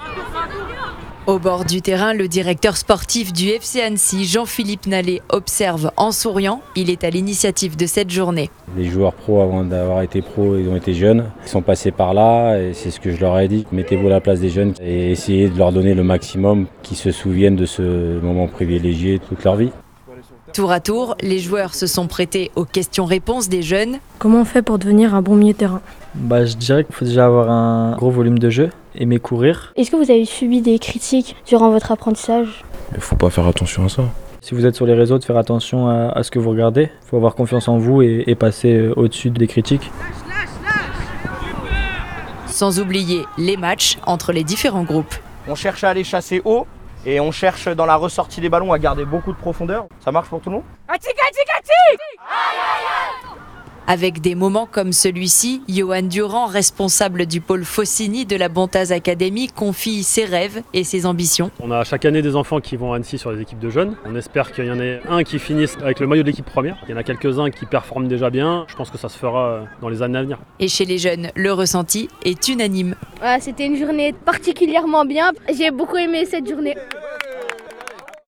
L’un des temps forts de cette rencontre était le question/réponse auquel les membres du FCA se sont consacrés à tour de rôle.
Les jeunes footballeurs ont pu poser différentes questions aux professionnels.